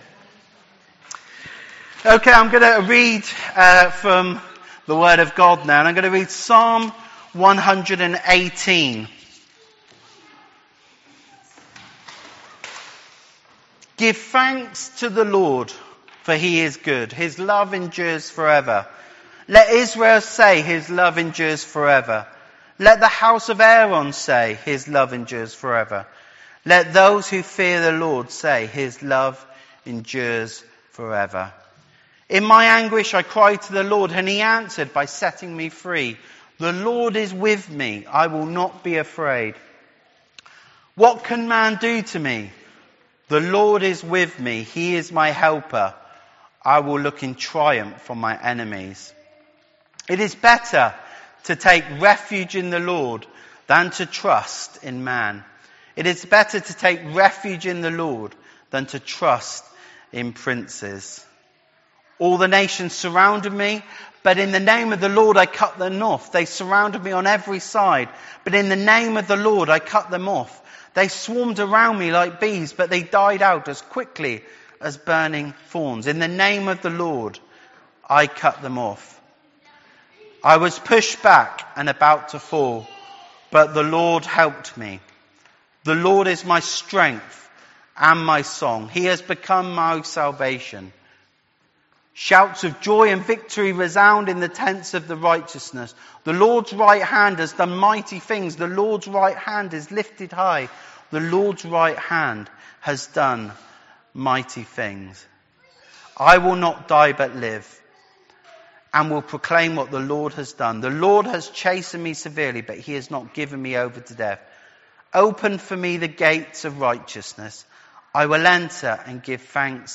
Listen to the Education Sunday talk here.